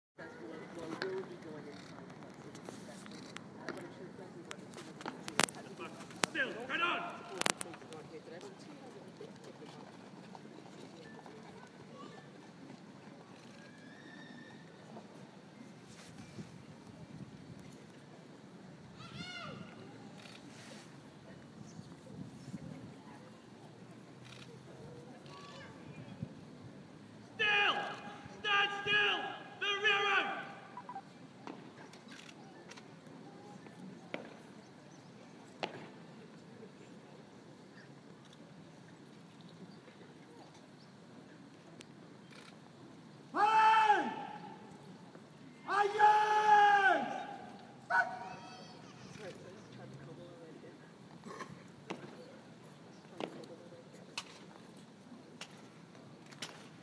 What hundreds of soldiers standing to attentio sound like